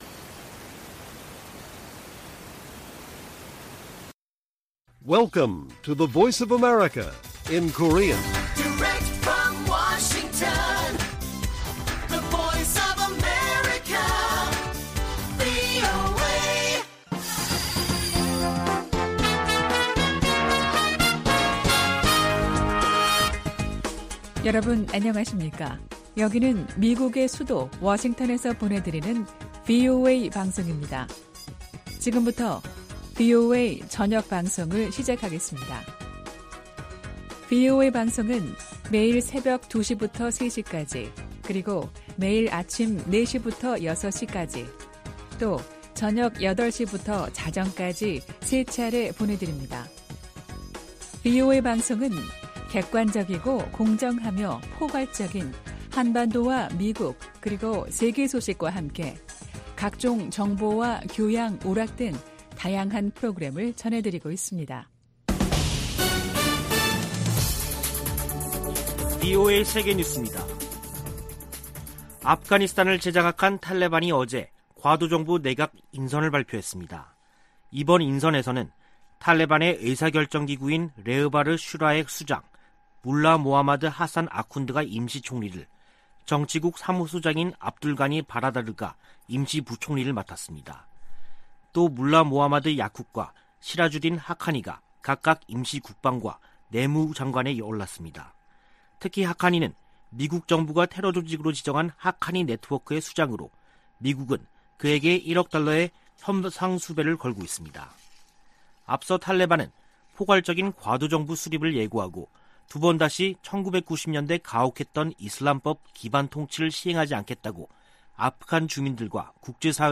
VOA 한국어 간판 뉴스 프로그램 '뉴스 투데이', 2021년 9월 8일 1부 방송입니다. 북한이 9일 열병식을 개최한다면 신형 무기 등장 여부가 최우선 관심사 가운데 하나라고 미국의 전문가들은 밝혔습니다. 미국인 10명 중 5명은 북한의 핵 프로그램에 대해 크게 우려하는 것으로 나타났습니다. 중국과 러시아가 유엔 안전보장이사회에서 대북 제재 완화 분위기를 띄우고 있는 가운데 미국은 제재를 계속 이행할 것이라는 입장을 밝혔습니다.